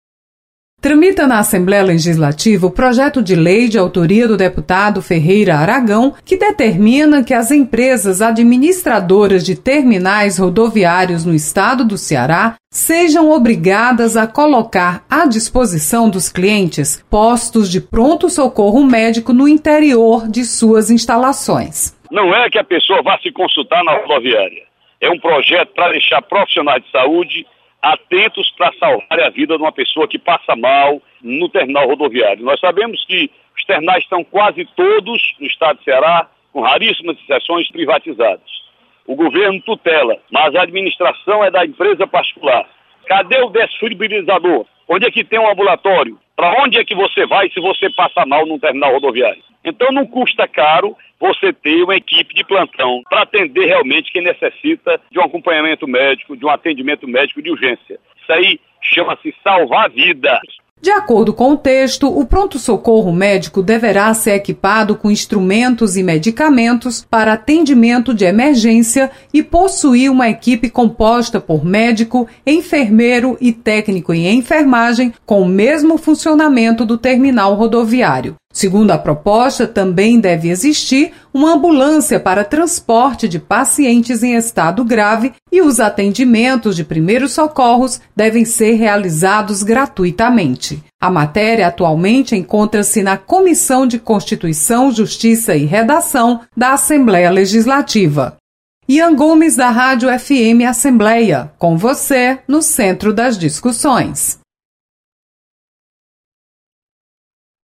Projeto obriga instalação de pronto socorro médico em terminais rodoviários. Repórter